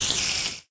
spider1.ogg